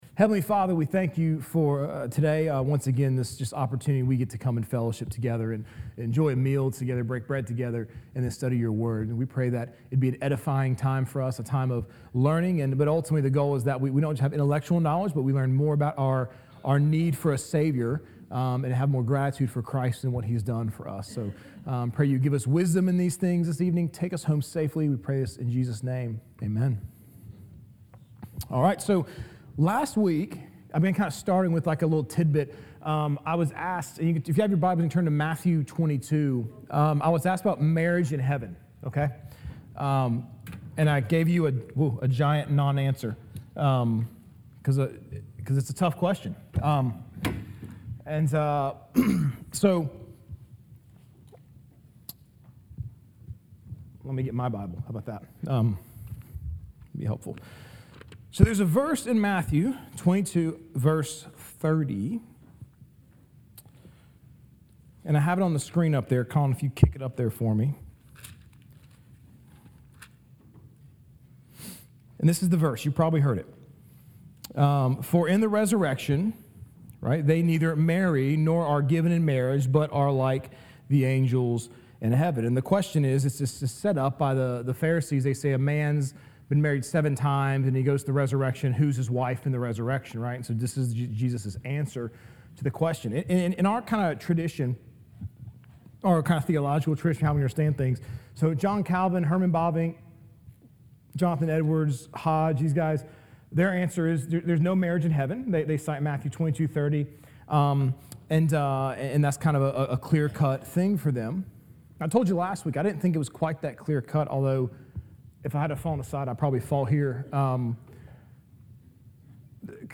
Sermons | Grace Fellowship (EPC)
Wednesday Night Spring Study 2025